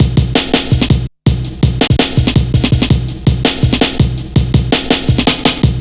Index of /90_sSampleCDs/Zero-G - Total Drum Bass/Drumloops - 3/track 45 (165bpm)
double garage 3.wav